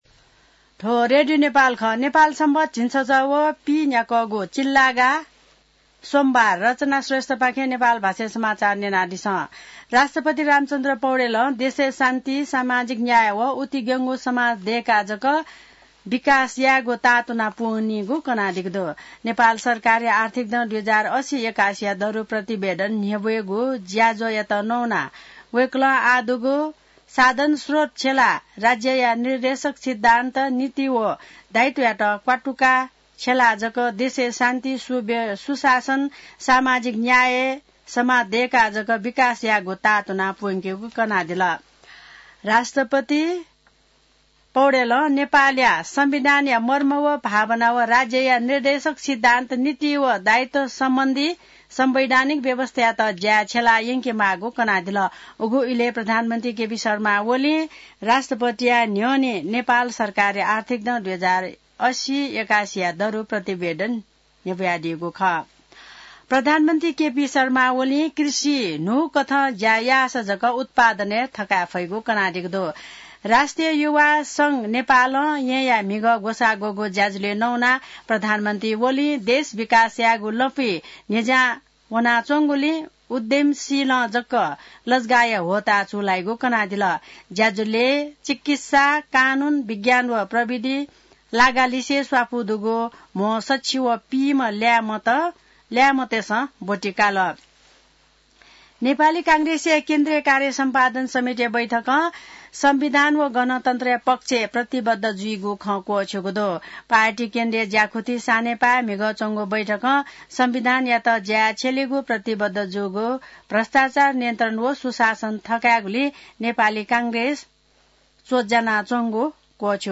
An online outlet of Nepal's national radio broadcaster
नेपाल भाषामा समाचार : ११ चैत , २०८१